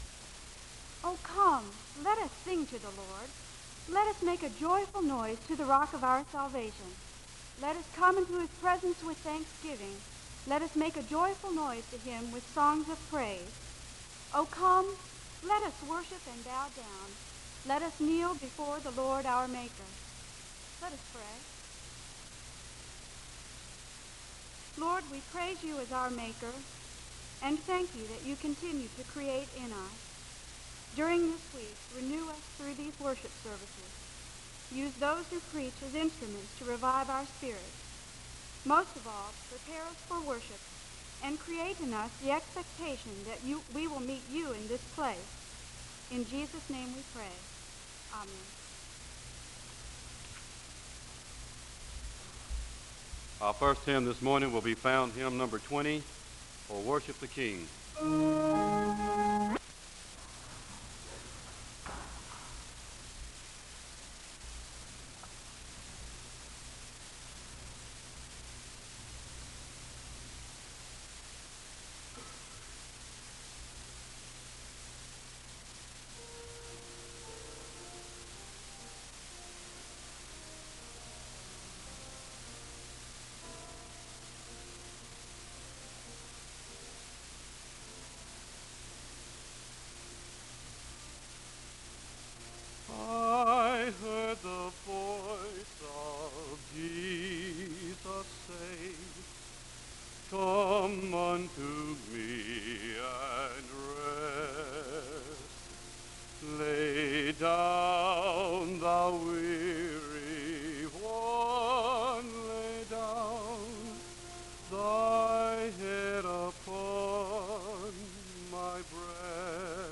The service begins with a word of prayer (00:00-01:00). A man sings a song of worship (01:01-05:10). The speaker reads from Jeremiah 17:5-8 (05:11-06:06).
Hymns